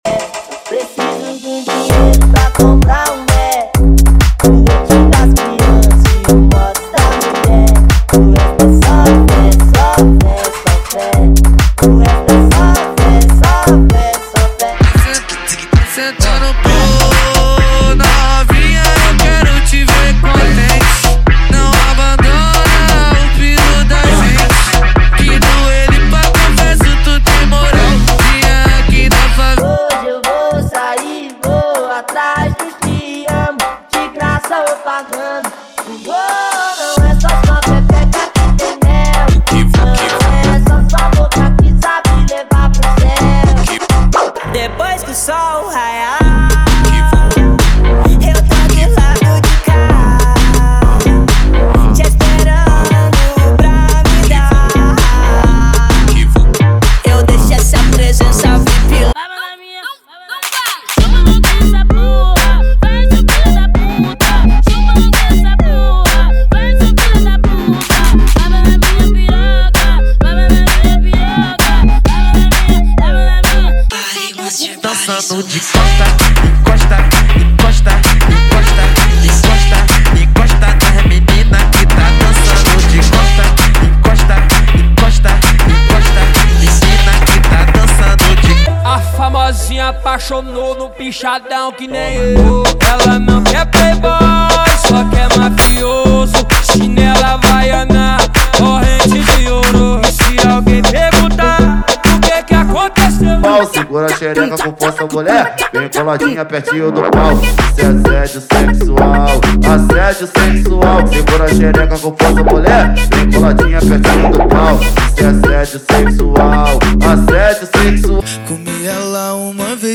• Eletro Funk = 50 Músicas
• Sem Vinhetas
• Em Alta Qualidade